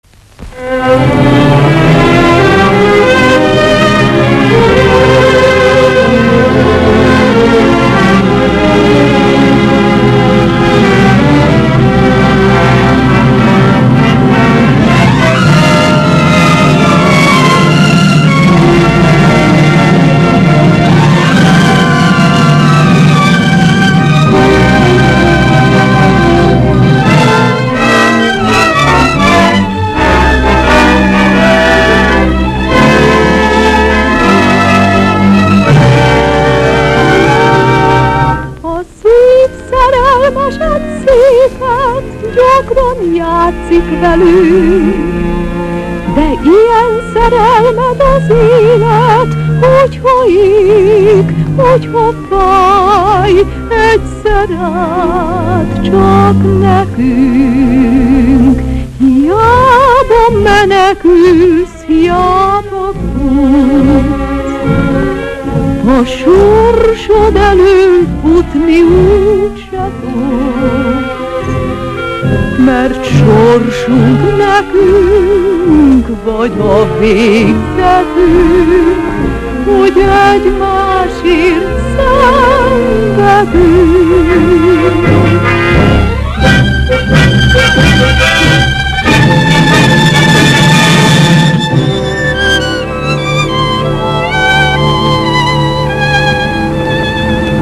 - "медленный фокстрот"
Звук подлинного граммофона 1935 г.)